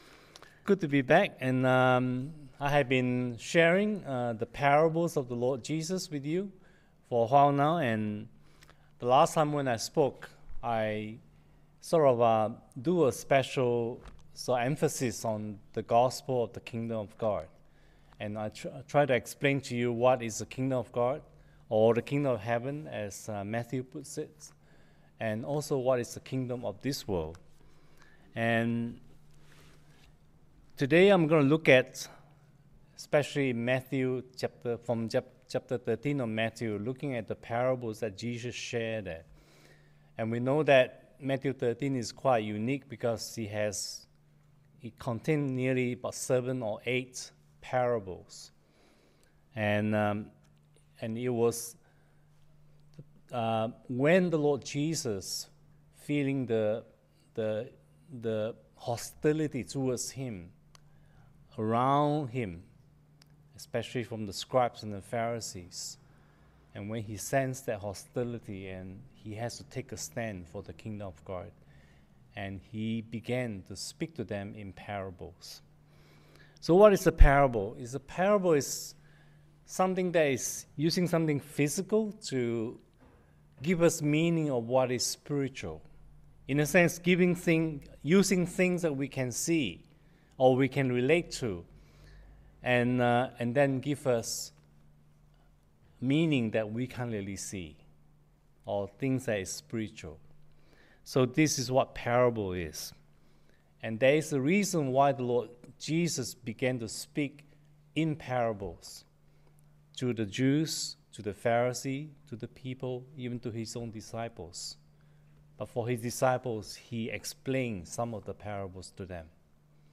Sunday-Ministry-8-October-2023.mp3